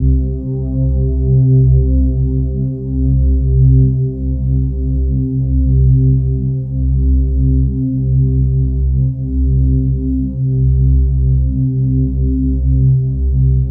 808s
M Metro Sidechain Bass.wav